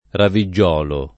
vai all'elenco alfabetico delle voci ingrandisci il carattere 100% rimpicciolisci il carattere stampa invia tramite posta elettronica codividi su Facebook raviggiolo [ ravi JJ0 lo ] (oggi lett. raviggiuolo [ ravi JJU0 lo ]) → raveggiolo